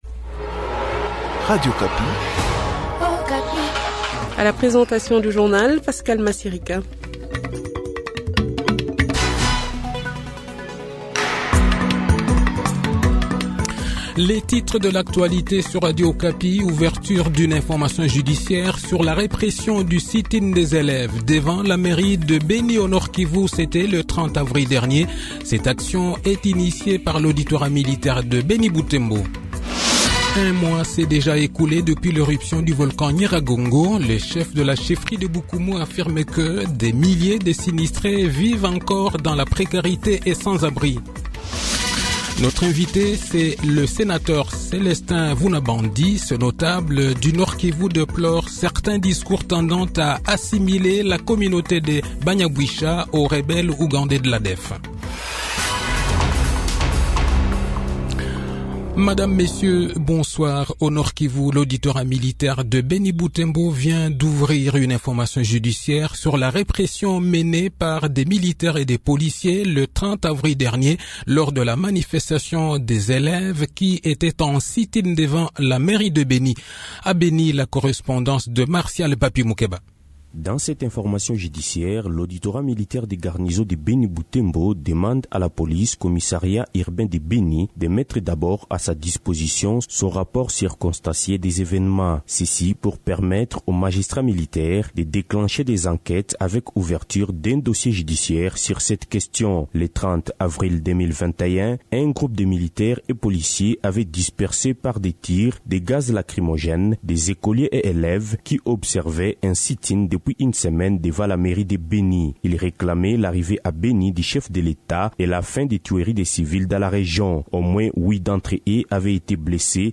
Le journal de 18 h,22 Juin 2021